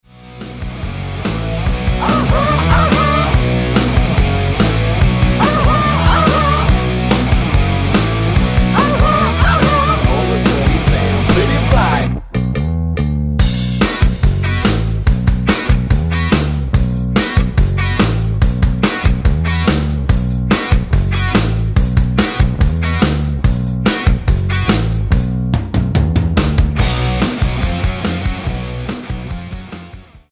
--> MP3 Demo abspielen...
Tonart:B mit Chor